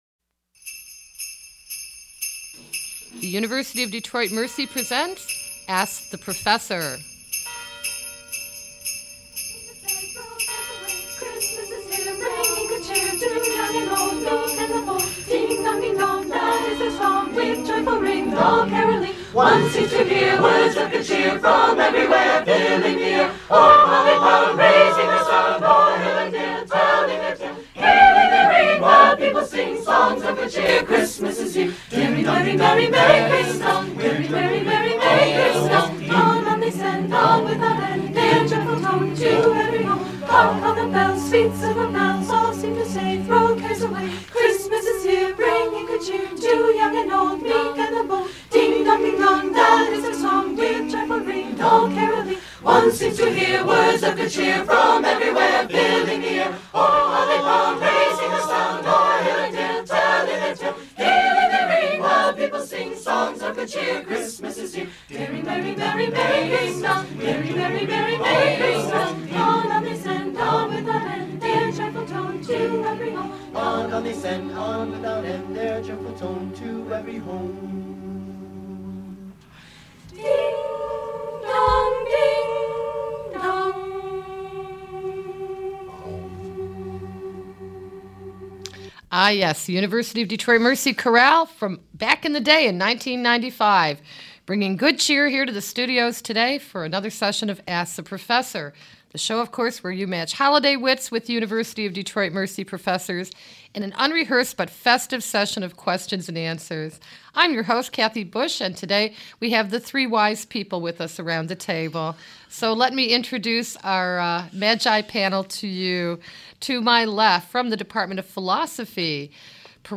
University of Detroit Mercy's broadcast quiz show